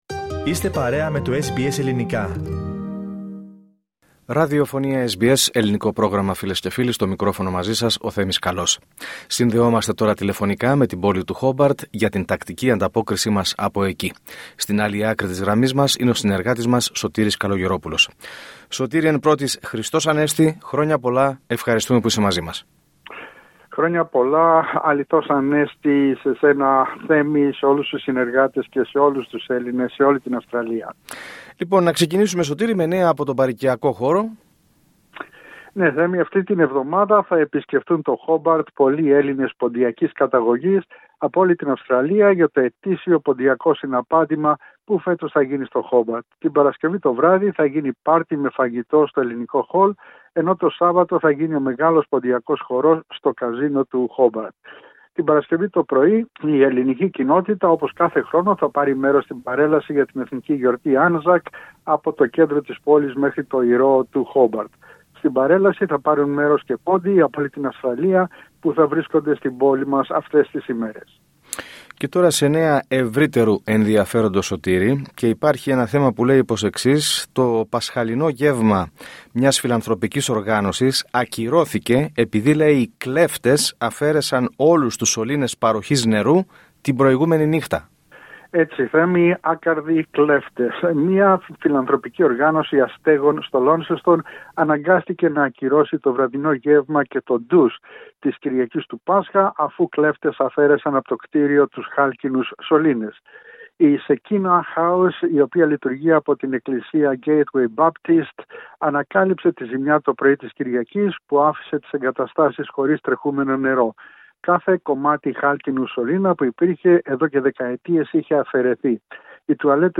Ακούστε την εβδομαδιαία ανταπόκριση από την Τασμανία